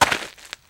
STEPS Gravel, Walk 02.wav